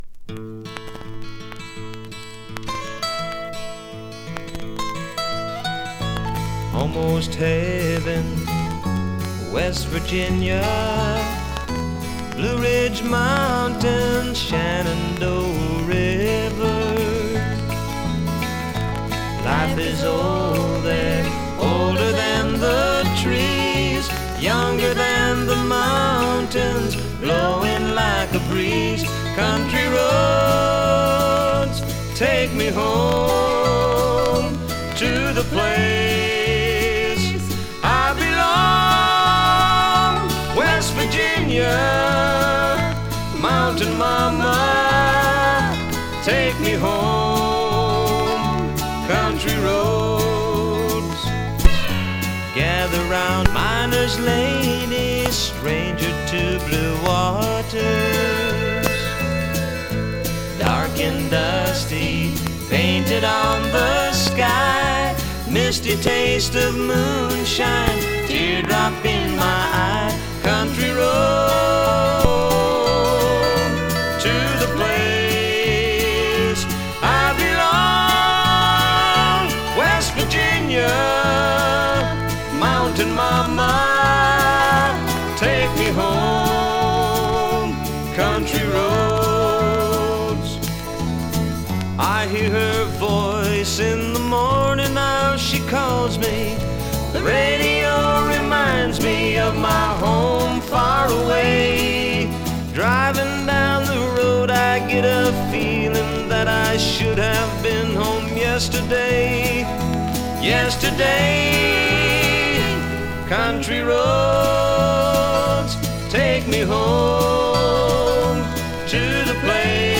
Too bad it skips like a dog with three legs.